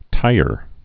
(tīər)